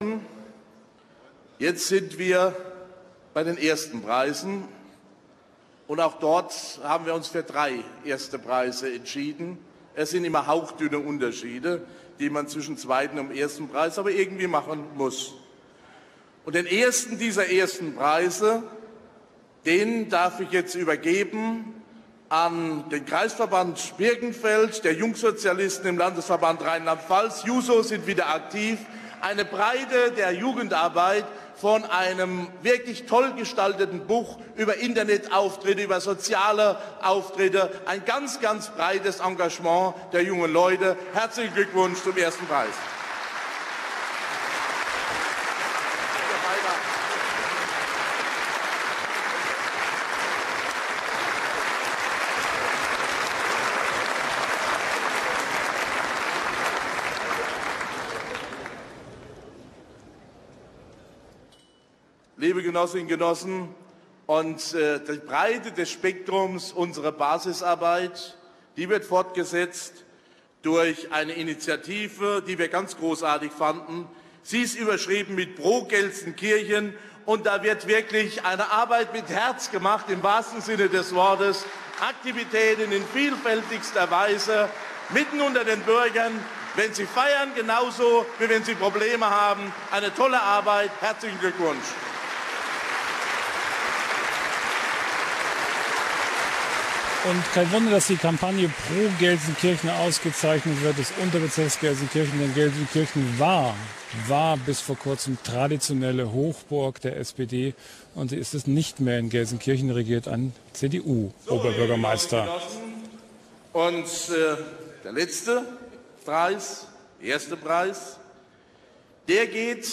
Die Jusos des Landkreis Birkenfeld sind mit dem „Wilhelm-Dröscher-Preis 2003“ ausgezeichnet worden. Bei der Preisverleihung, die live im Fernsehen ausgestrahlt wurde, überreichte den Jusos Ministerpräsident Kurt Beck den Preis.